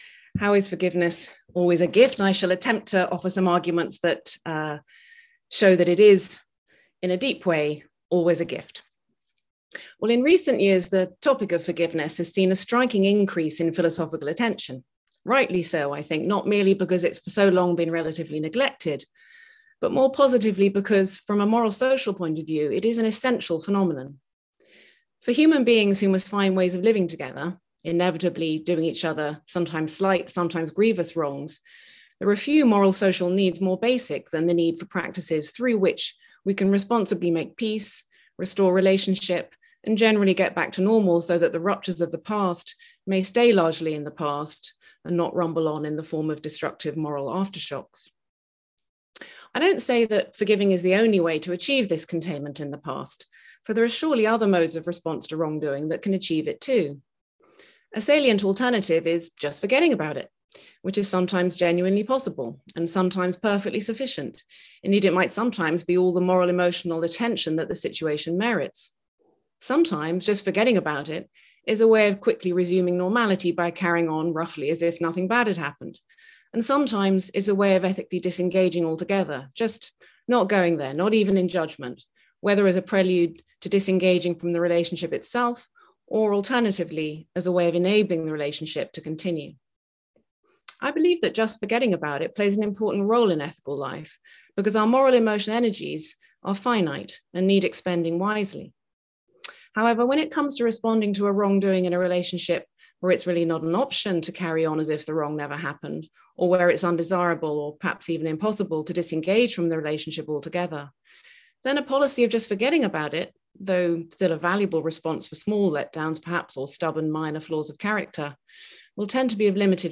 2022-Eastern-Presidential-Address-—-Miranda-Fricker.mp3